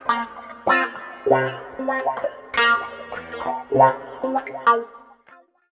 90 GTR 1  -R.wav